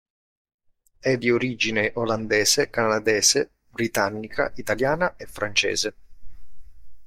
An user Italian